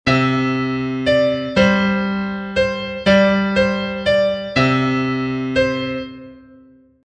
Une partition très simple.
L’intensité et le timbre, traités secondairement, ne sont présents que sous la forme  d’annotations plus ou moins précises : « très doux » pour la première, ou indication de l’instrument (ici, la voix « Basse ») pour le second.